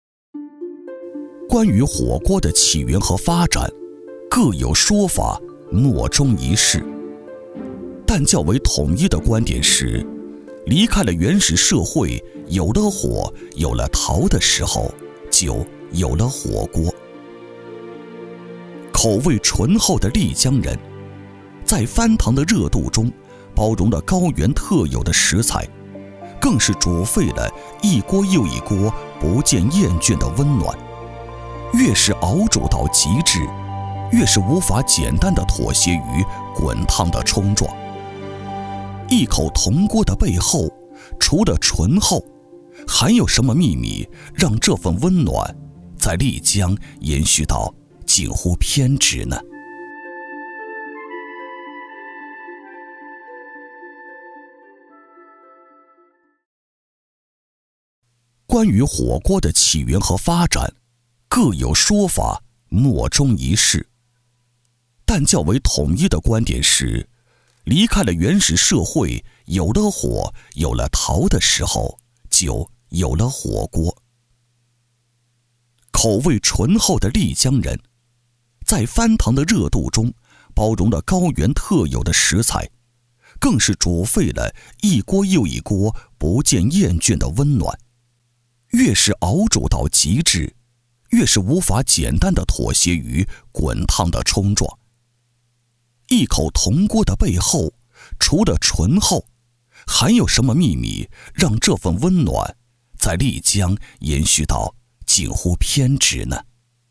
男国94_纪录片_舌尖_纳西铜火锅.mp3